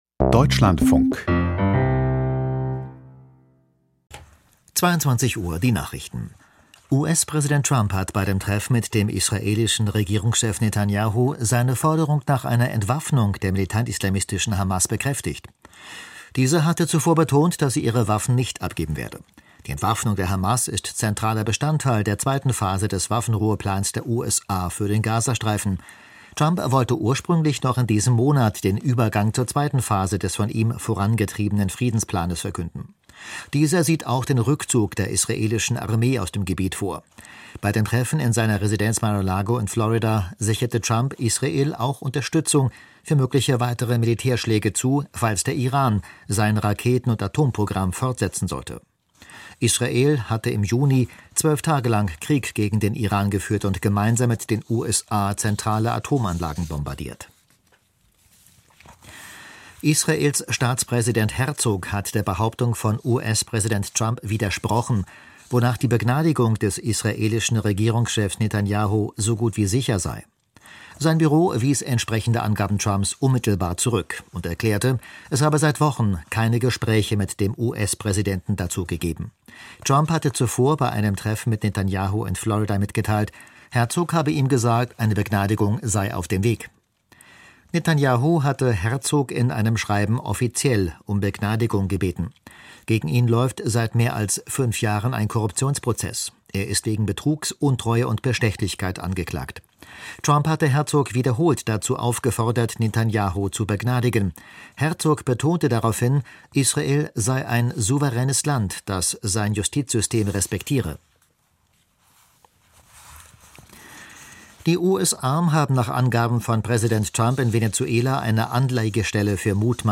Die Nachrichten vom 29.12.2025, 22:00 Uhr